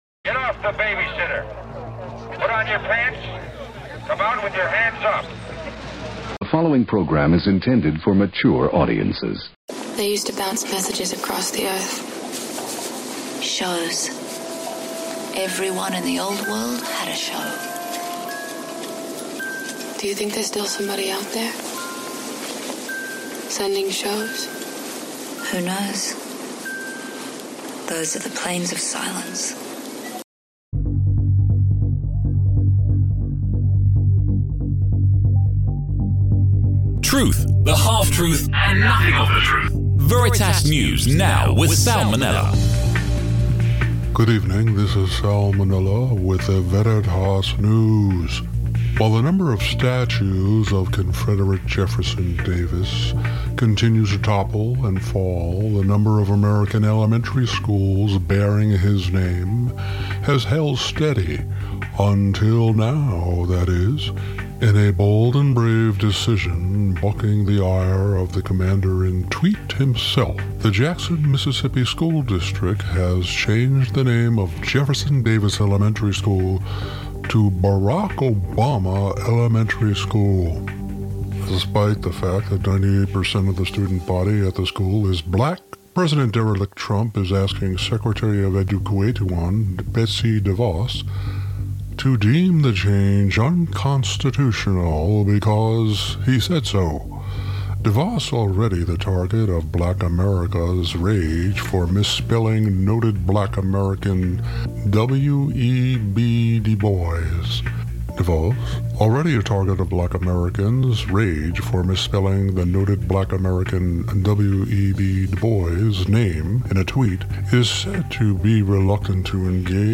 Relish in the comedic/musical interlude with the original song “Tiki Torch Nazis.”
Most importantly, U.S. Senator (R) Jeff Flake of Arizona delivers what may turn out to be an historic speech against so-called president D. Trump and his fascist administration.
Next, enjoy Kristin Bell’s “Pink Sourcing,” a satirical radio spot taking at jab at gender pay inequality extolling the advantages of hiring the lower-paid, nicer-smelling, weaker sex.